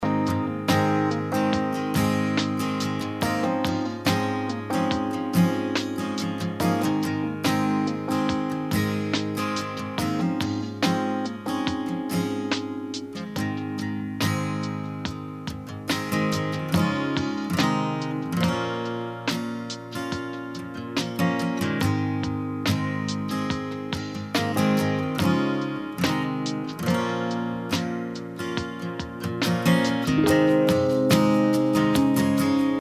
B/Trax – High Key without Backing Vocals